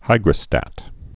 (hīgrə-stăt)